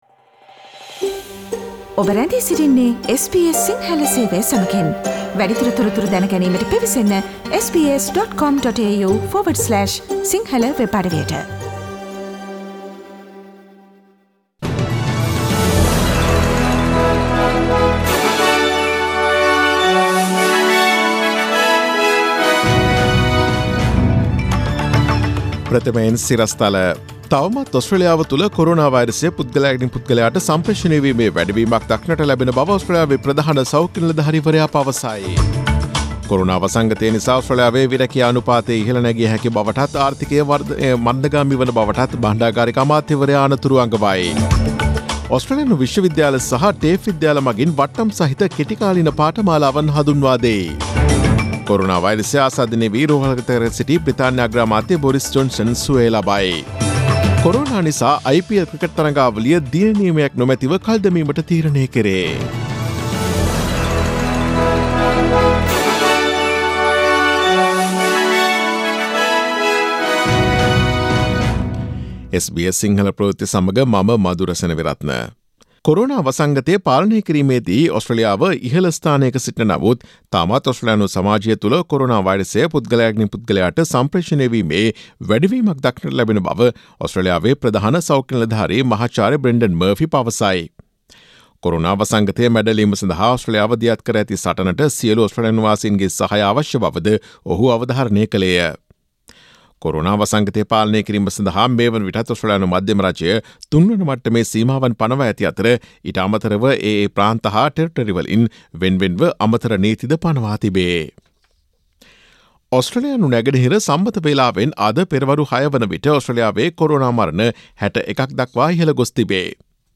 Daily News bulletin of SBS Sinhala Service: Monday 13 April 2020
Today’s news bulletin of SBS Sinhala Radio – Monday 13 April 2020 Listen to SBS Sinhala Radio on Monday, Tuesday, Thursday and Friday between 11 am to 12 noon